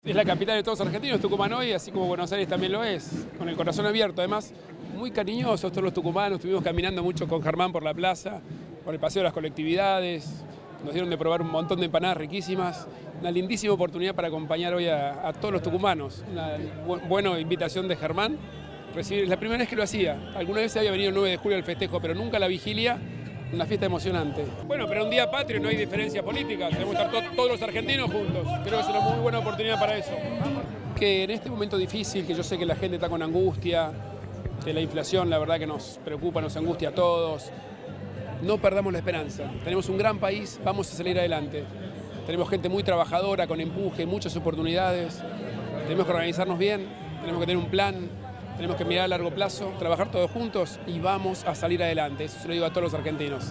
Rodríguez Larreta participó de la vigilia por el Día de la Independencia en Tucumán
Corte audio hrl tucuman